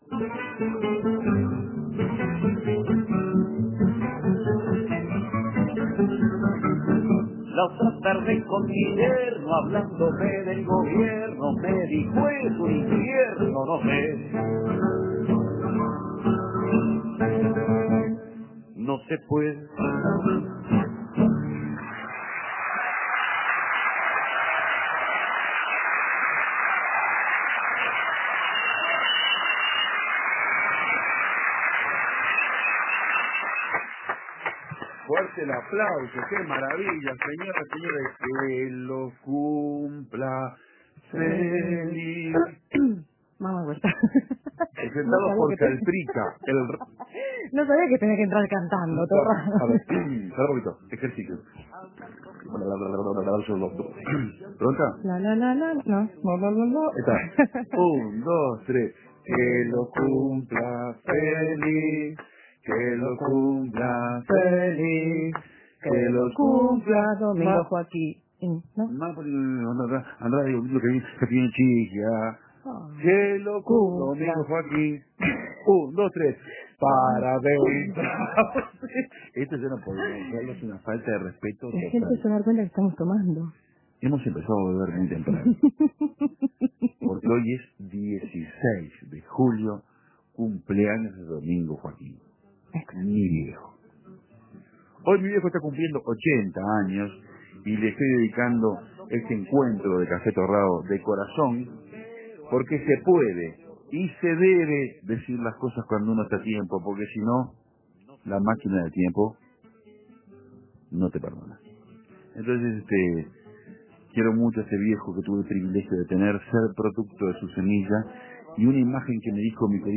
¿Ancla o una gesta gloriosa? Desde el corazón homenajeamos a los uruguayos que lograron aquella victoria, con Alcides Ghiggia por teléfono. Y un especial que vincula a Eduardo Galeano y el relato del entrañable Solé.